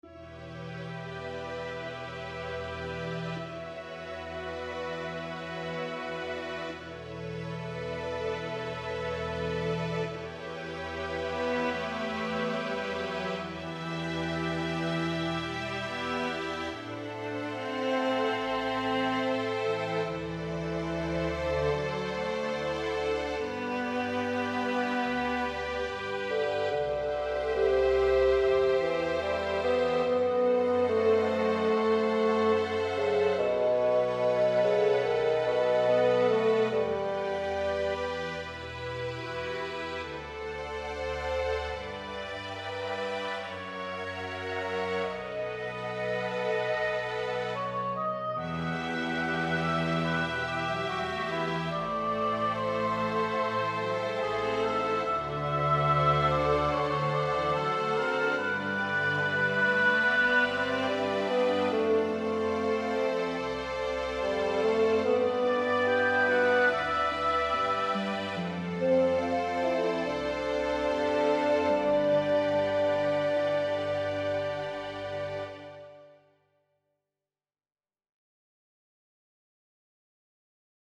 The first version is the original Notion 3 score that you posted, and the only modifications are to replace the original instruments with Miroslav Philharmonik instruments, and this version has a Flugelhorn . . .
La-nuit-Leila-v5-MP-Flugelhorn.mp3